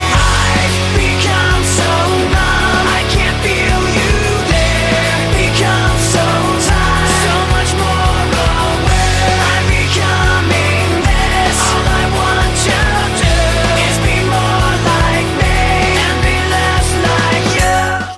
• Category Rock